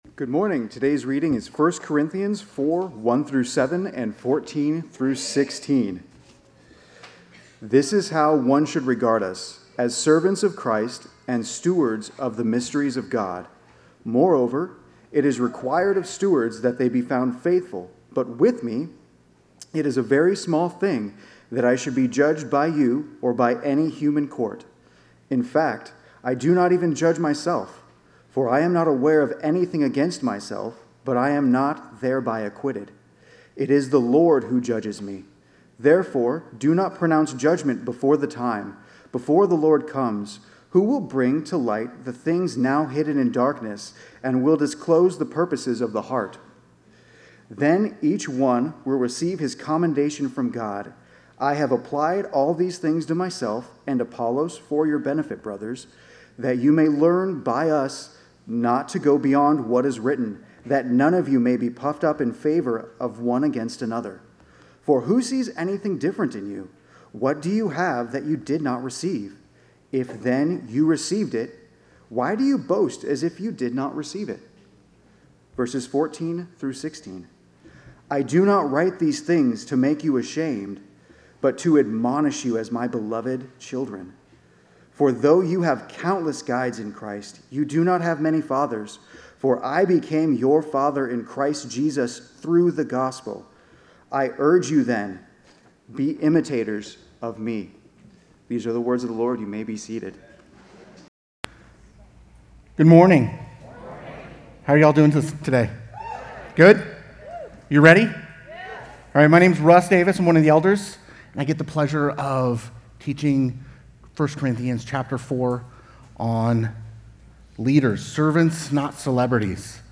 Sermon Notes: Leaders: Servants, not celebrities